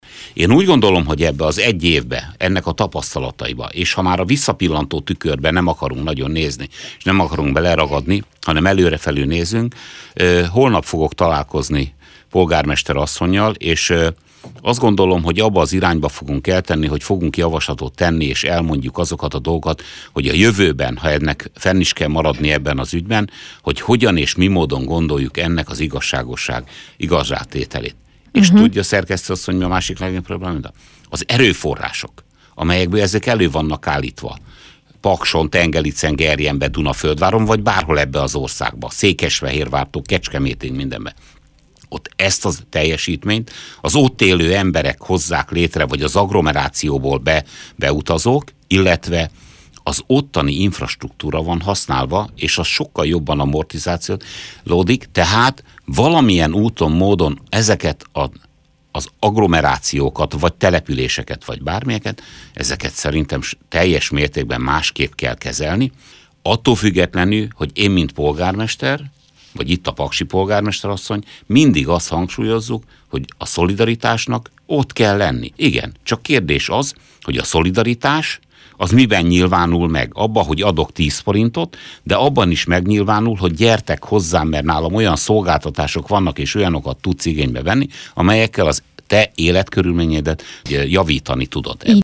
Az elmúlt év tapasztalatai alapján javaslattal élnek majd a döntéshozók felé a Versenyképes Járások Program kapcsán a paksi térség befizetésre kötelezett önkormányzatai annak érdekében, hogy igazságosabb legyen a rendszer és azok a térségek fejlődjenek, amik befizetők. Horváth Zsolt Dunaföldvár polgármestere ezt annak kapcsán mondta el rádiónknak, hogy a november 11-én megjelent Magyar Közlöny értelmében Dunaföldvárnak 918 millió forintot kell befizetni az államkasszába, melynek csak töredéke jut vissza Dunaföldvárra és a közvetlen térségébe.